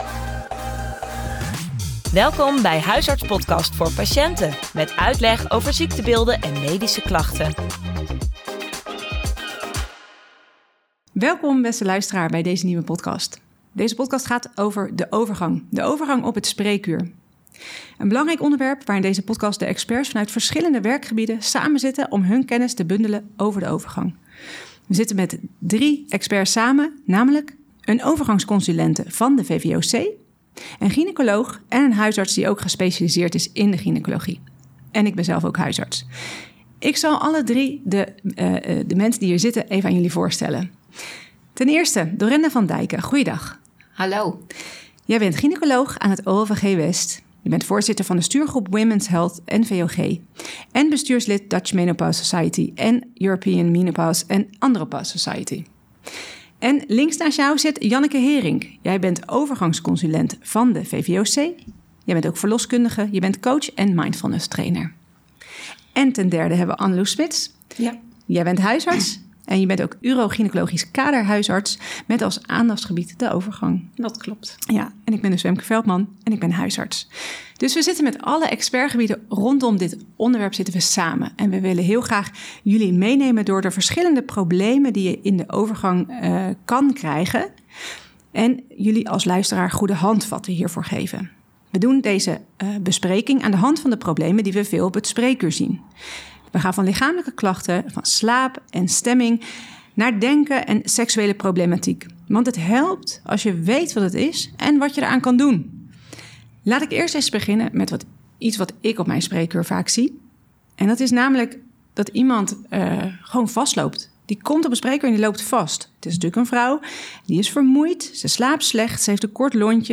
In deze podcast bespreken experts vanuit de drie verschillende werkgebieden de belangrijkste problemen rondom de overgang. Van lichamelijke klachten, slaap, stemming, denken tot sexuele problematiek, met de bijbehorende goede handvatten zodat jij weet wat je kan doen!